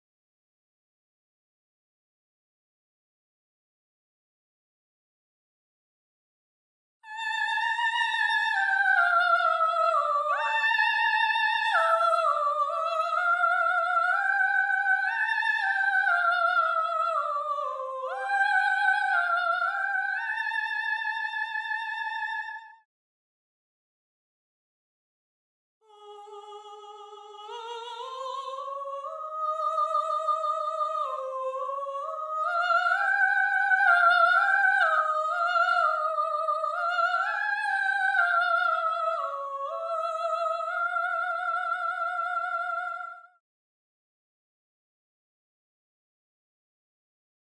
Voice 1 (Soprano/Soprano)
gallon-v8sp5-20-Soprano_0.mp3